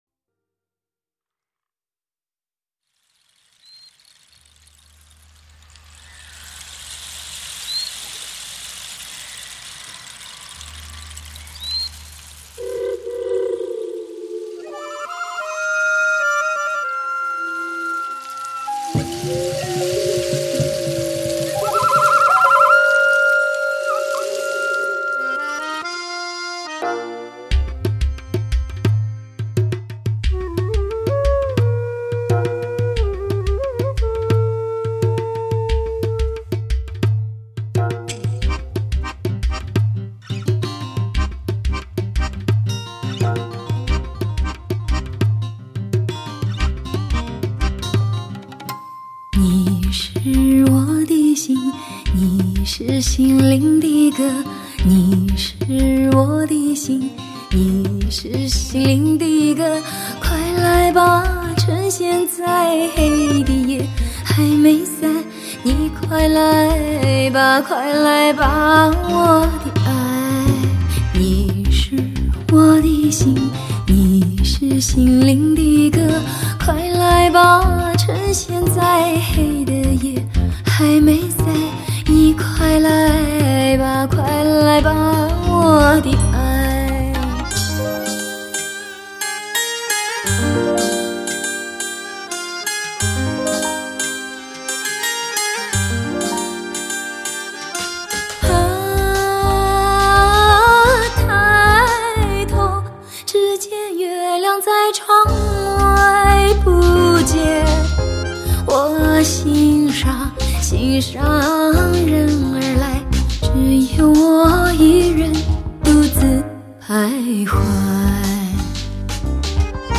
最具创意的音乐编排，异域风情音画，神秘而动感，
天籁般的女声吟唱，带领您进入梦幻般的神话国度。